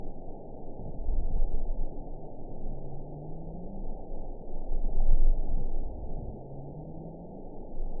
event 910379 date 01/21/22 time 00:04:02 GMT (3 years, 10 months ago) score 7.30 location TSS-AB07 detected by nrw target species NRW annotations +NRW Spectrogram: Frequency (kHz) vs. Time (s) audio not available .wav